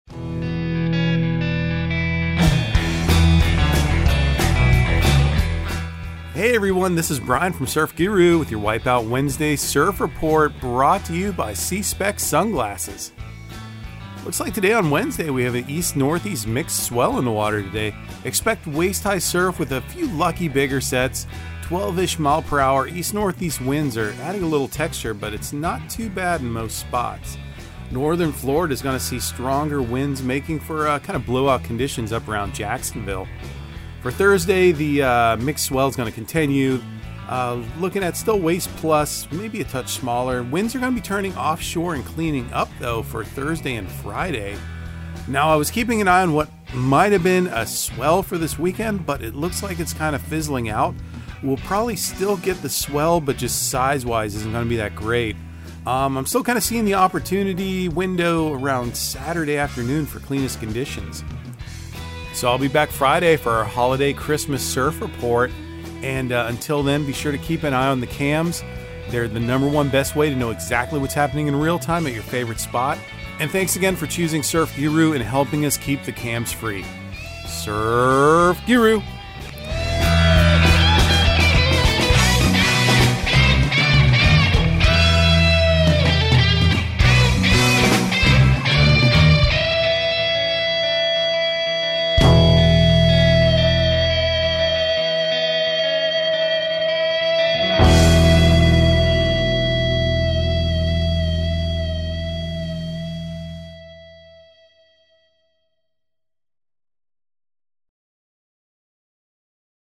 Surf Guru Surf Report and Forecast 12/21/2022 Audio surf report and surf forecast on December 21 for Central Florida and the Southeast.